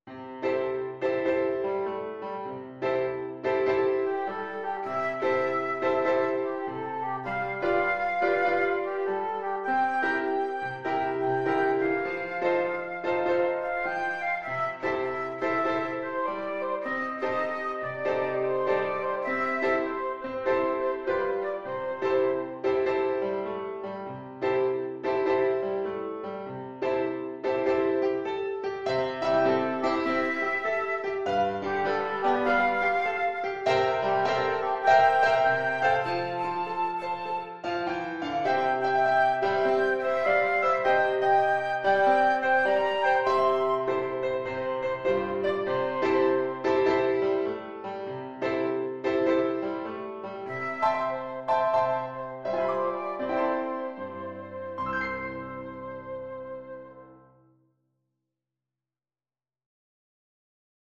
Flute
C major (Sounding Pitch) (View more C major Music for Flute )
Moderate swing
Traditional (View more Traditional Flute Music)